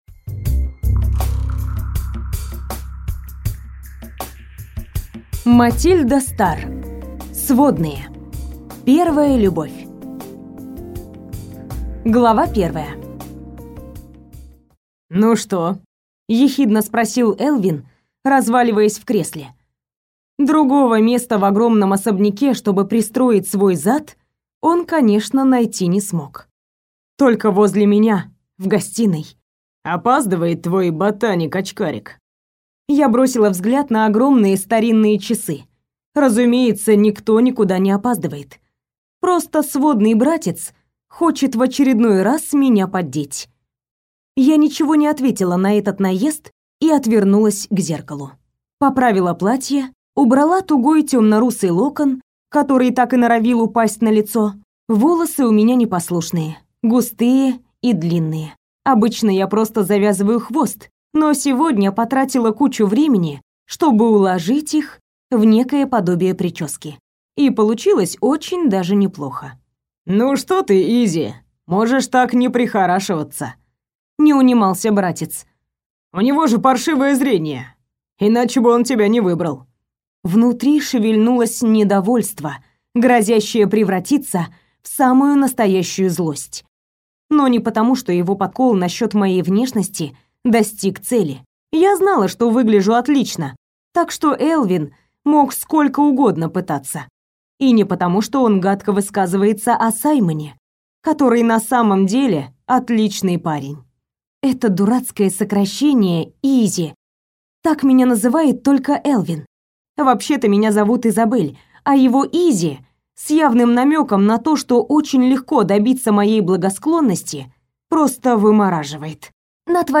Аудиокнига Сводные | Библиотека аудиокниг
Прослушать и бесплатно скачать фрагмент аудиокниги